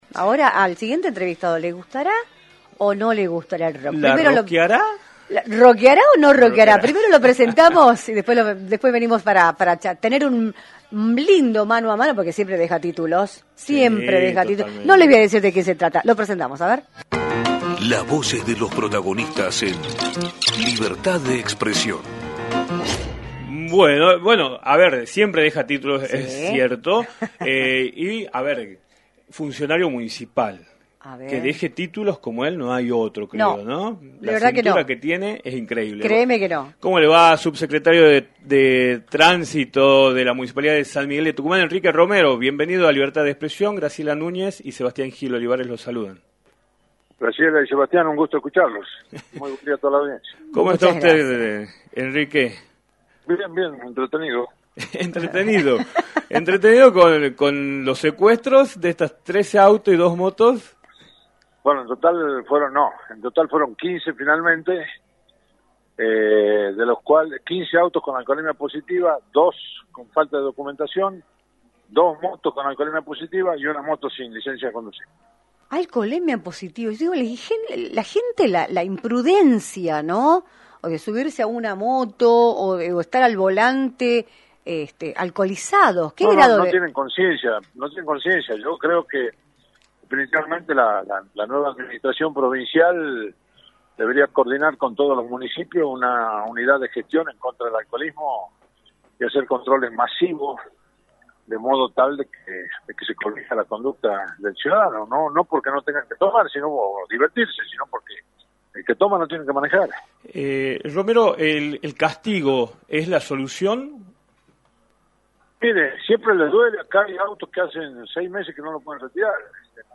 Enrique Romero, Subsecretario de Tránsito de la Municipalidad de San Miguel de Tucumán, informó en “Libertad de Expresión”, por la 106.9, como se encuentra la situación vehicular en la Capital, luego de que se confirmara el secuestro de 15 vehículos por diversas circunstancias como alcoholismo positivo y falta de papeles, y analizó el escenario político provincial.